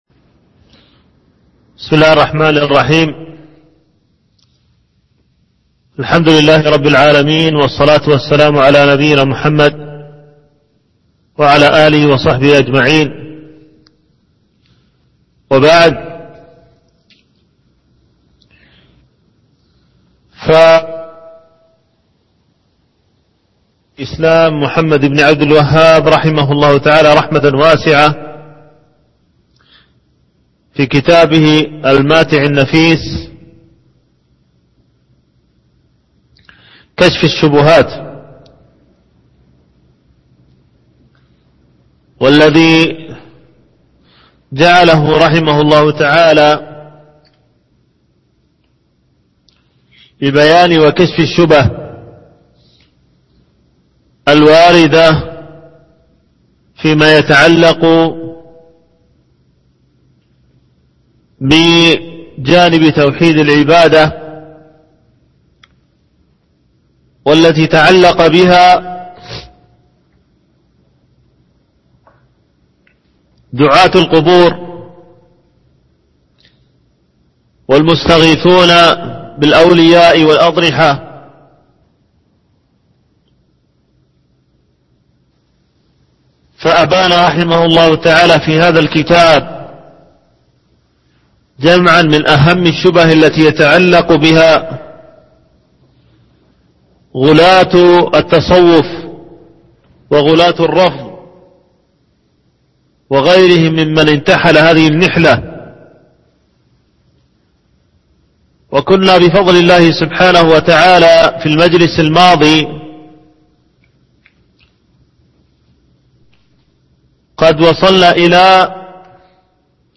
شرح كشف الشبهات - الدرس الحادي عشر